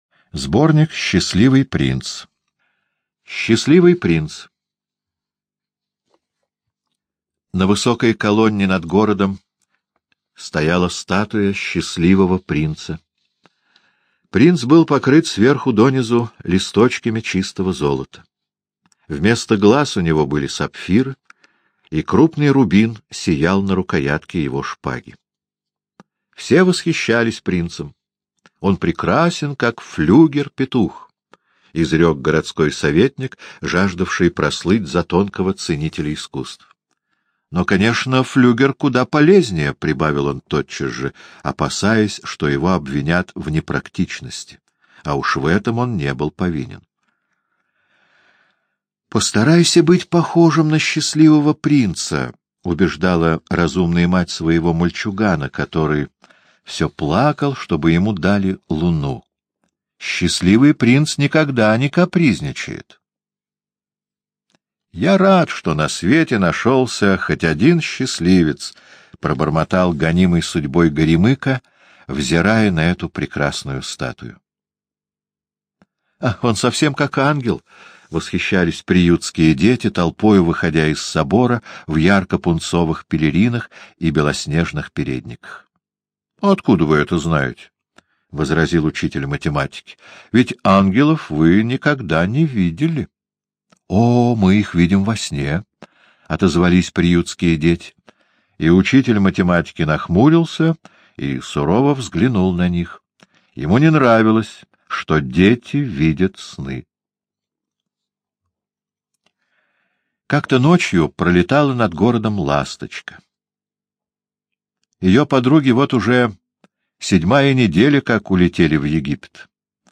Слушайте Счастливый принц — аудиосказку Уайльда О. Философская сказка о статуе принца и ласточке, о доброте и сострадании.